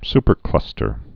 (spər-klŭstər)